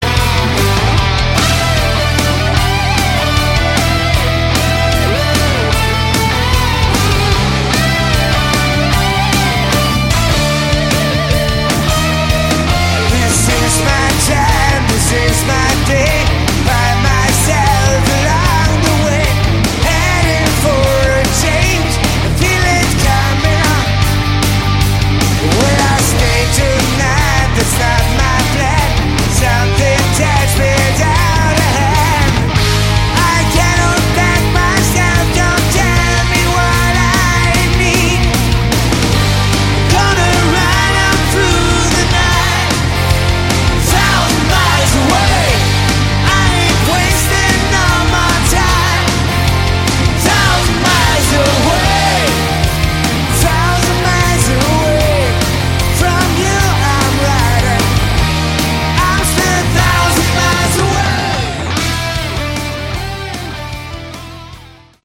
Category: Hard Rock
lead vocals, guitar
bass, vocals
keyboards, vocals
drums